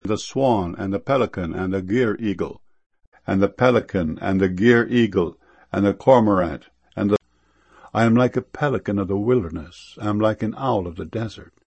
pelican.mp3